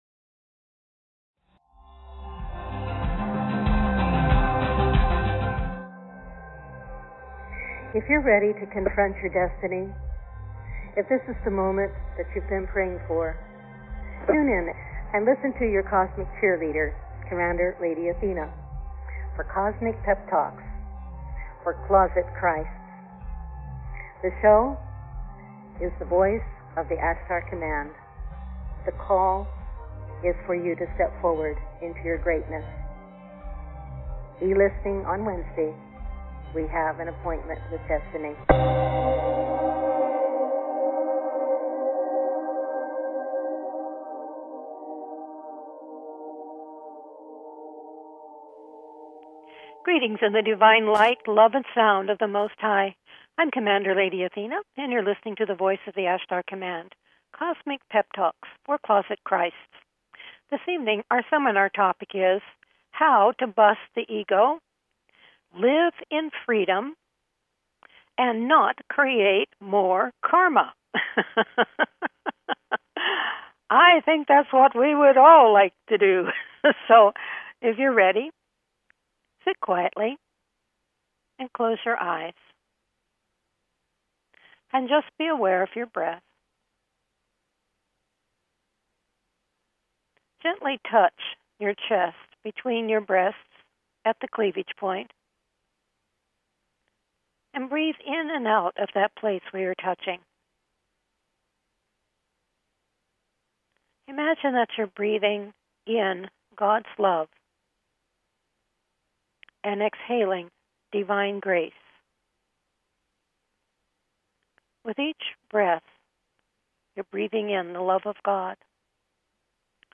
THE VOICE OF THE ASHTAR COMMAND radio shows serve to empower you in acquiring consciousness reference points for your present level of awareness.